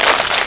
smack.mp3